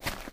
STEPS Dirt, Run 03.wav